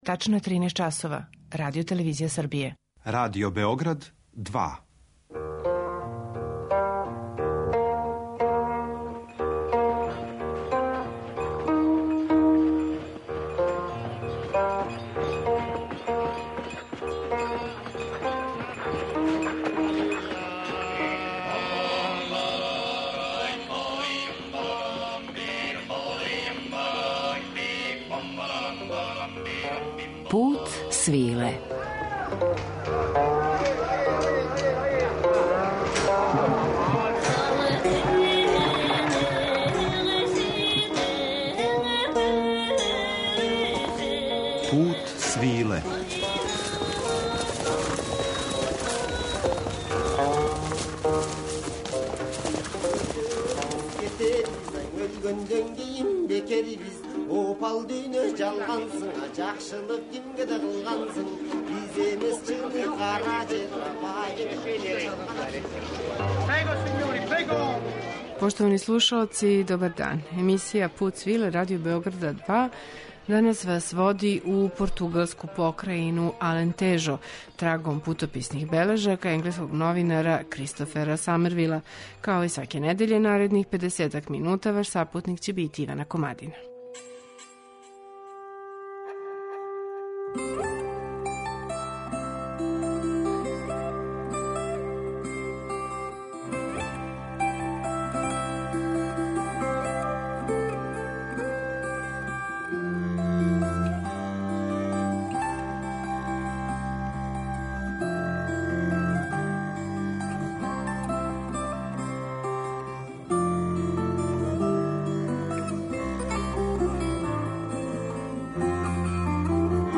Идиличну слику Алантежа у данашњем Путу свиле допуниће најпознатији музичар овог краја - Франсишко Наиа.